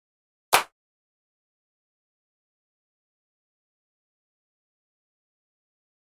クラップ音
よくある手拍子の音ですね。
HALION SONIC 7のHip Hop Kit 5にあるD#1の音です。